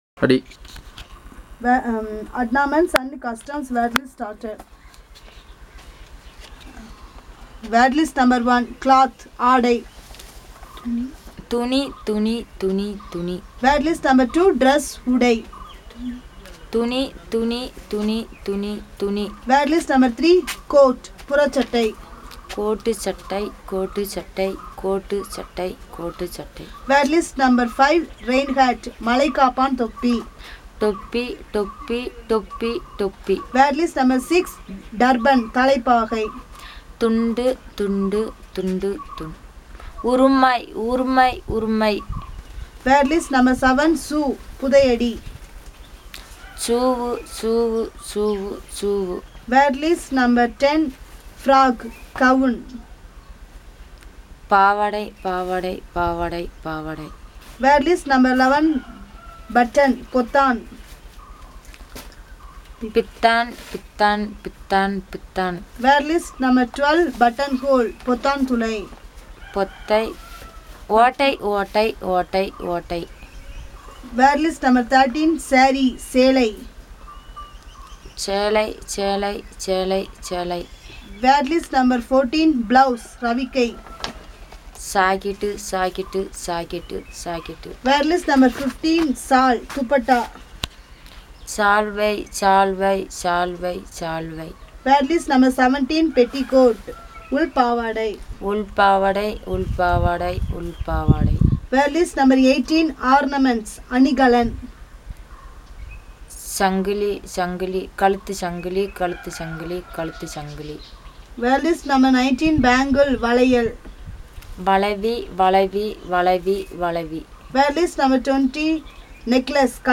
NotesThis an elicitation of words about adornment and costumes, using the SPPEL Language Documentation Handbook.